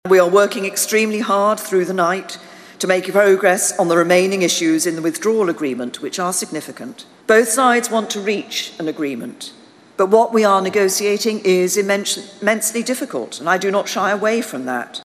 The British Prime Minister told a banquet in London last night that the Brexit process has been “immensely difficult”.
Mrs May says everyone is doing their best to break the deadlock: